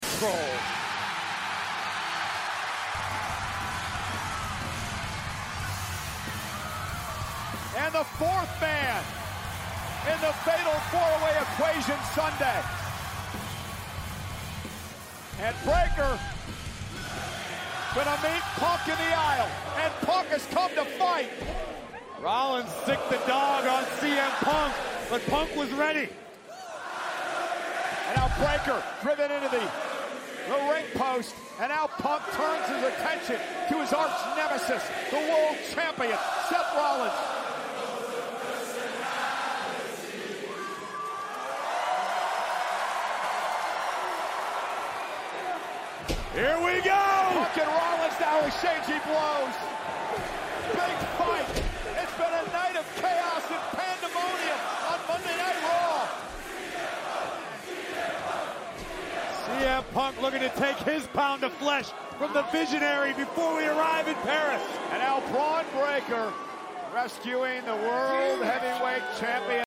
The crowd singing punks theme sound effects free download
The crowd singing punks theme was so sick 🔥🔥🔥